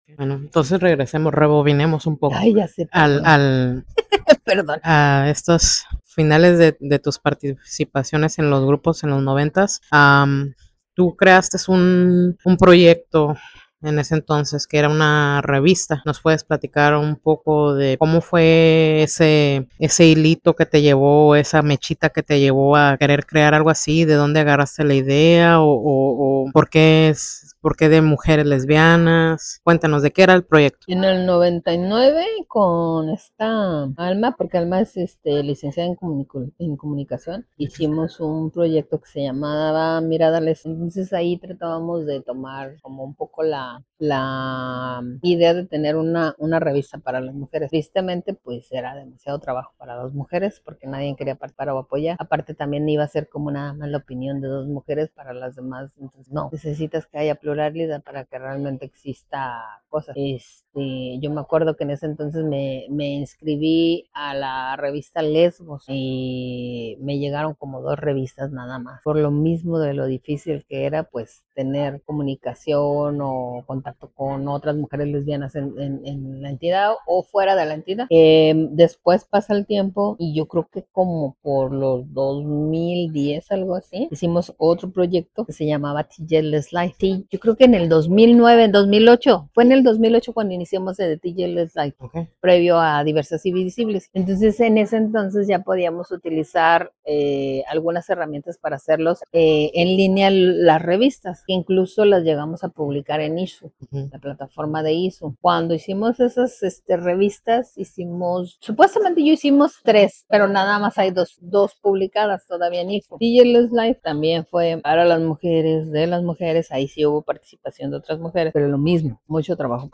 Parte dos de la entrevista